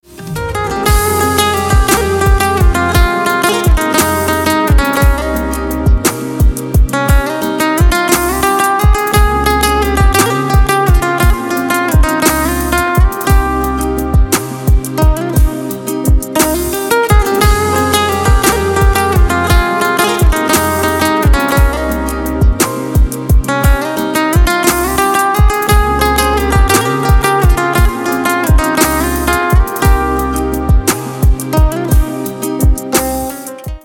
Спокойные звонки, спокойные рингтоны
Спокойные рингтоны